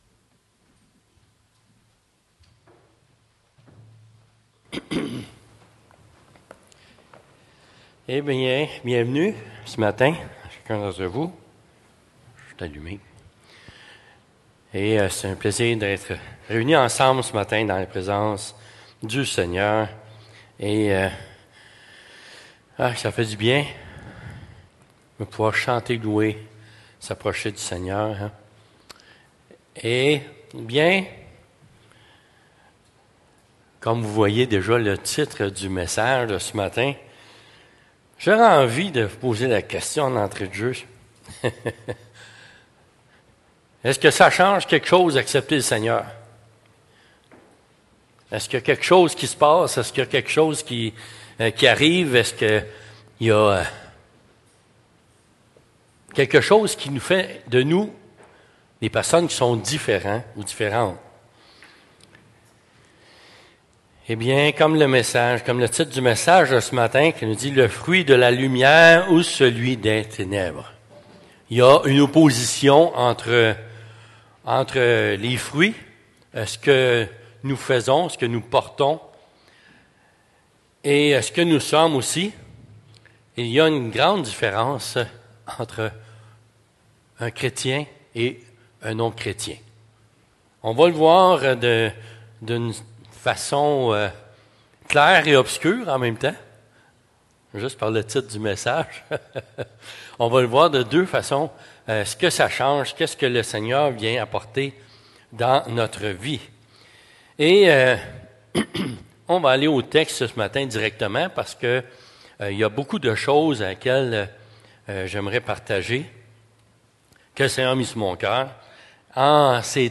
Prédication